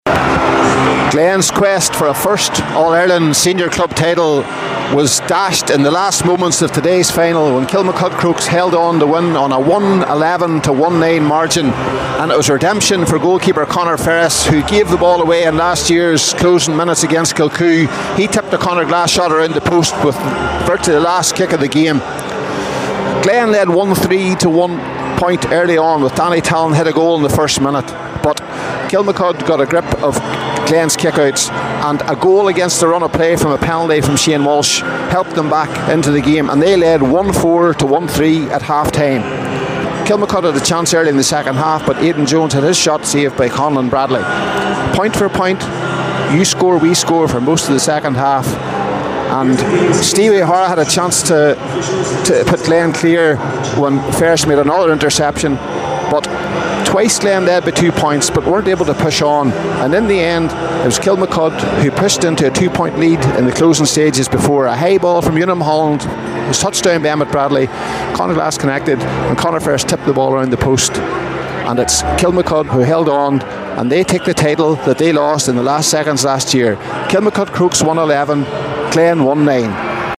full time report…